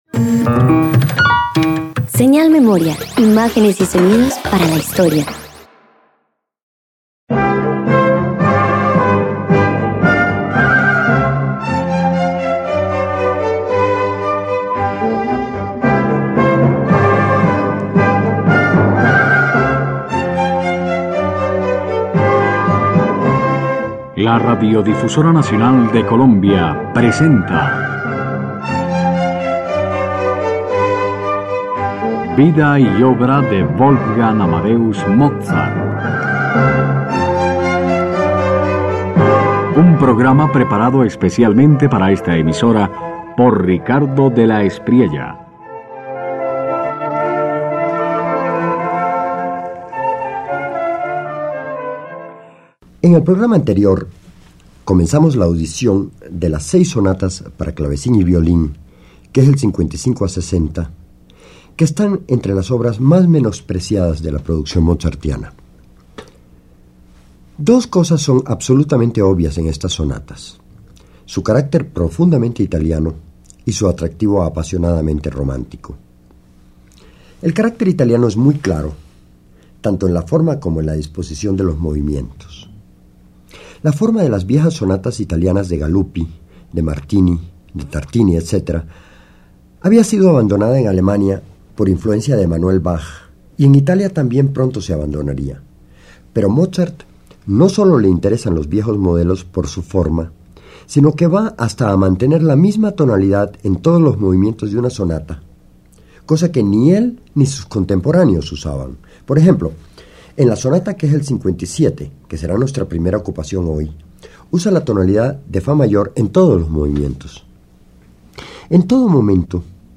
En estas sonatas, Mozart rompe con la tradición que relegaba al violín a tan solo un acompañante, dándole voz propia en un diálogo transparente con el clavecín, como si ambos compartieran una misma respiración íntima, profunda y compartida.
029 Sonatas para Claveci╠ün y Violi╠ün_1 2.mp3